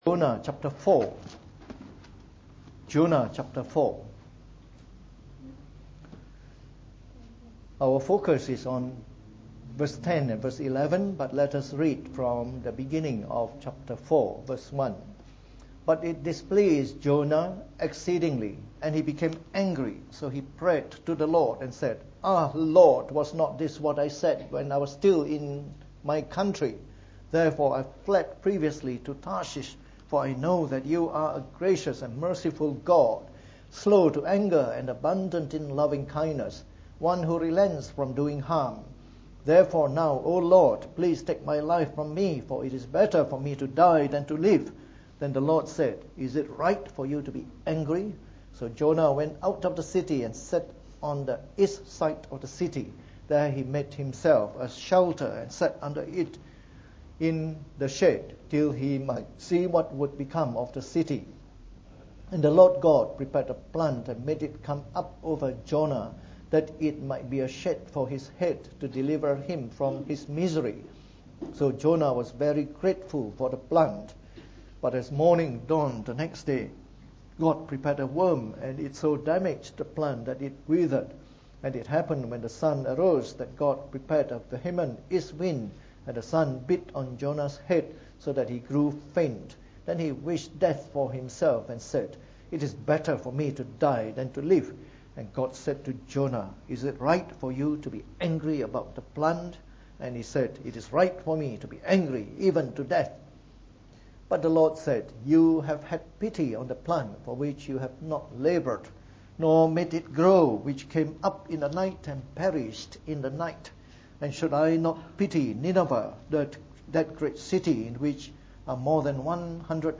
From our series on the Book of Jonah delivered in the Morning Service.